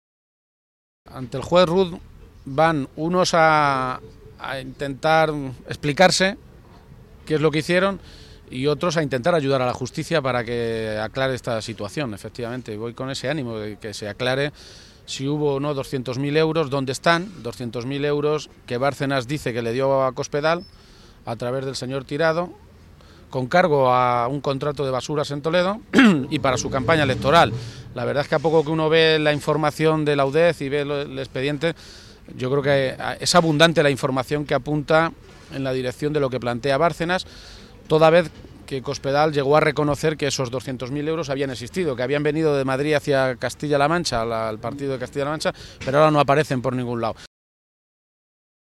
García-Page se pronunciaba de esta maneta esta mañana, en Toledo, a peguntas de los medios de comunicación, horas antes de que declare como testigo en la Audiencia Nacional en el marco de las investigaciones de los llamados “papeles de Bárcenas”.
Cortes de audio de la rueda de prensa